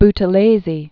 (btə-lāzē), Mangosuthu Gatsha Born 1928.